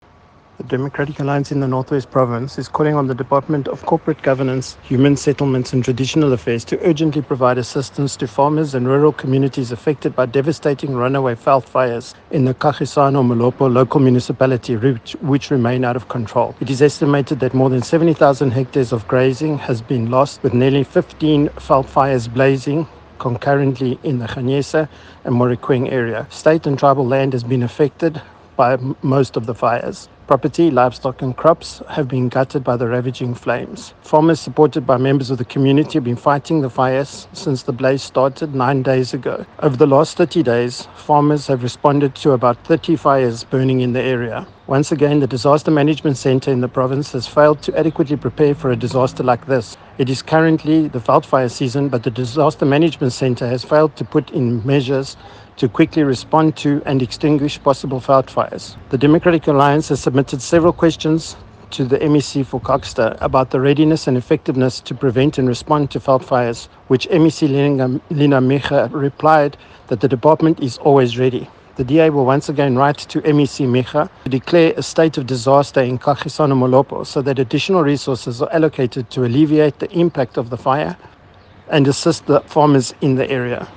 Issued by Gavin Edwards MPL – DA North West Spokesperson on Agriculture and Rural Development
Note to Editors: Please find the attached soundbite in